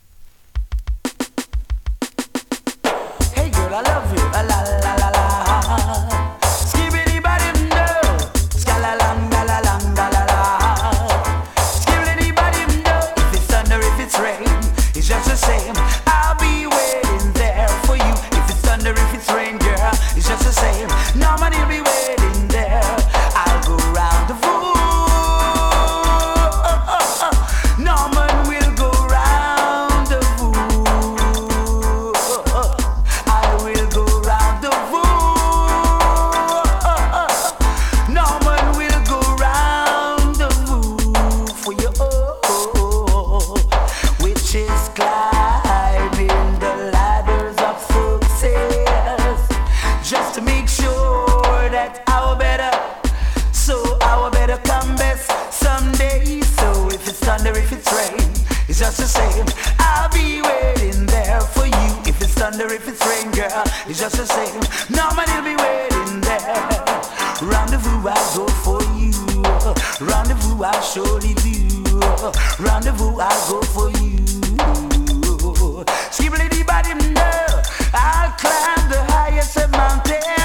2018 NEW IN!! DANCEHALL!!
スリキズ、ノイズかなり少なめの